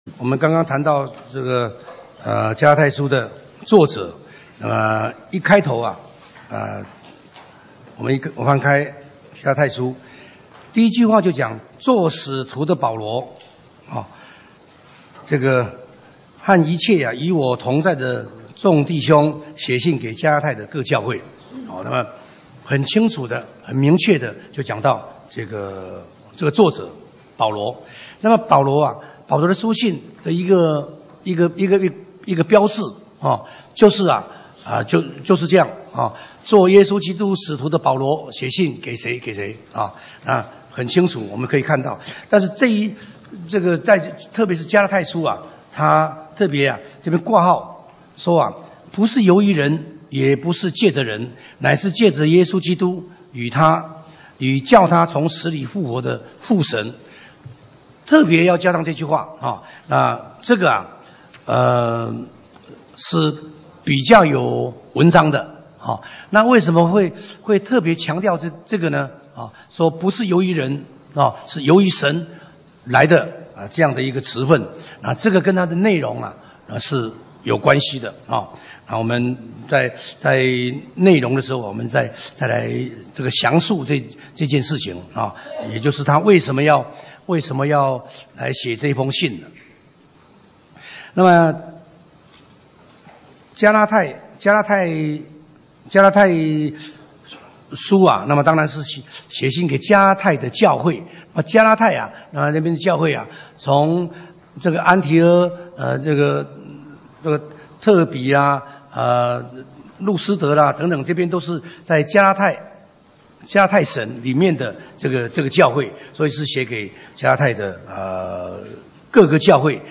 講習會
地點 台灣總會 日期 02/15/2017 檔案下載 列印本頁 分享好友 意見反應 Series more » • 加拉太書 15-1 • 加拉太書 15-2 • 加拉太書 15-3 …